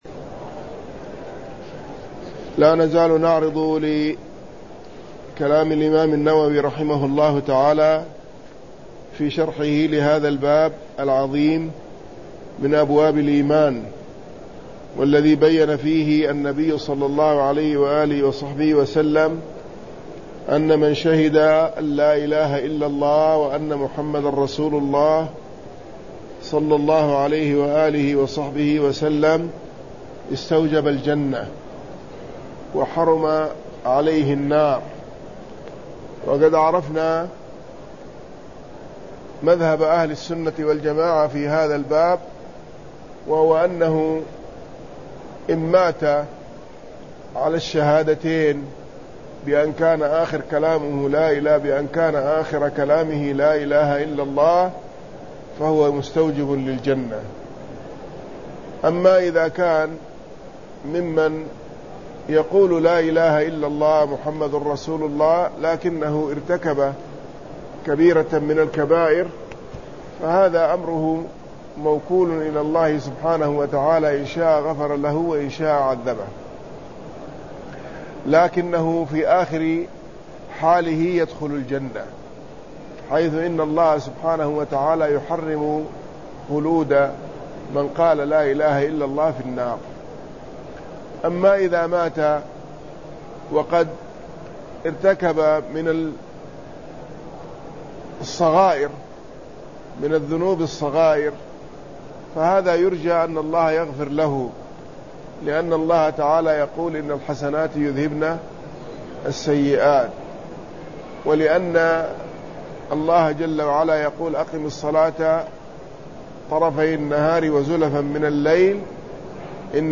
صحيح مسلم شرح